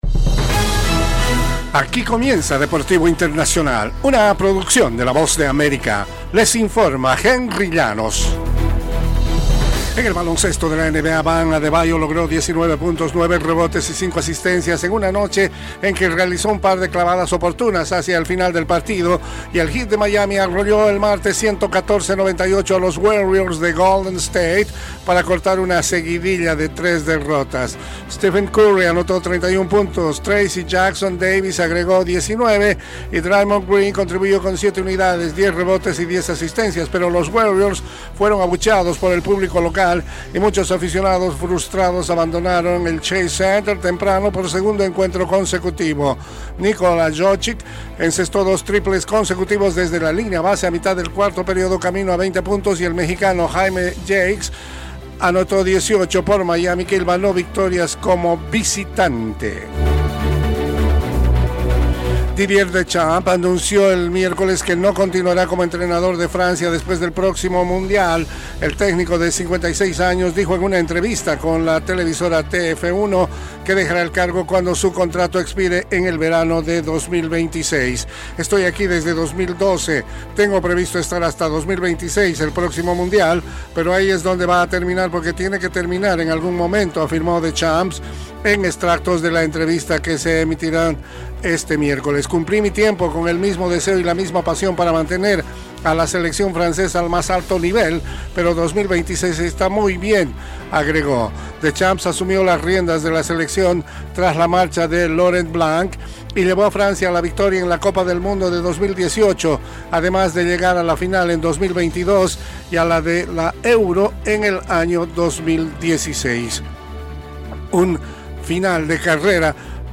Las noticias deportivas llegan desde los estudios de la Voz de América